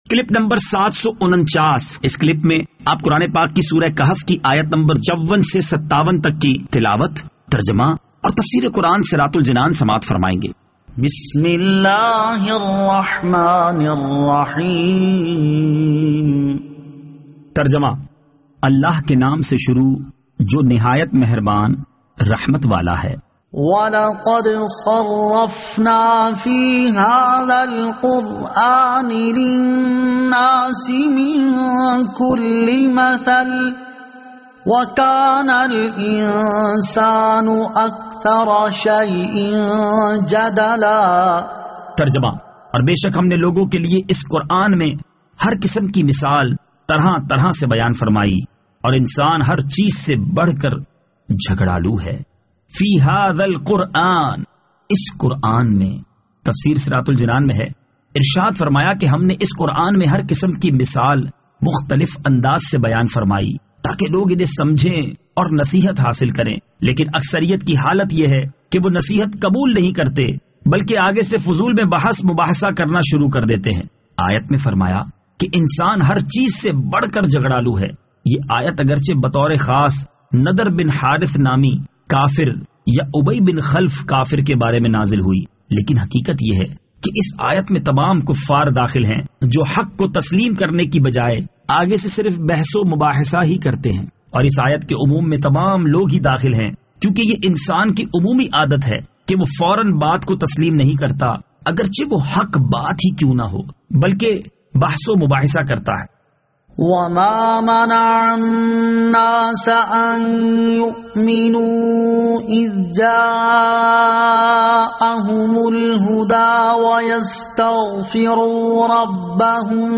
Surah Al-Kahf Ayat 54 To 57 Tilawat , Tarjama , Tafseer